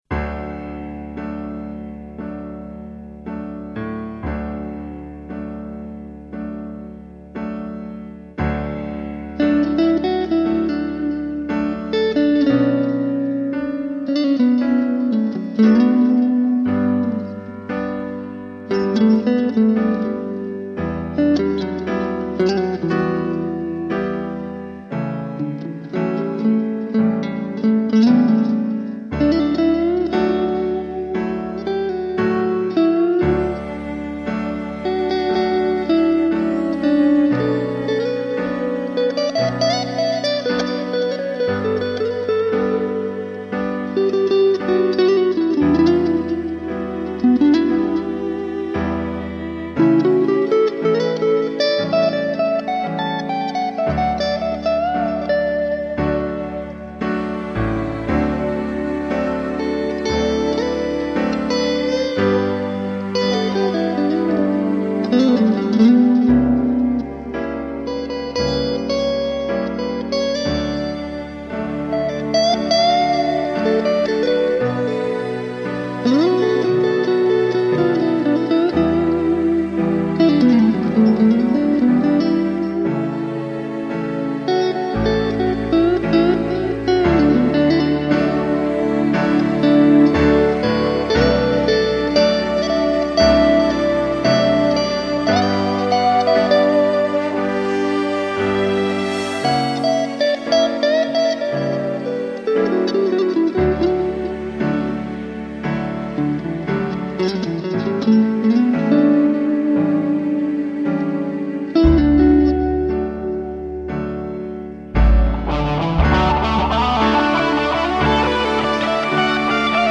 PISTES AUDIO (guitares & basse) + MIDI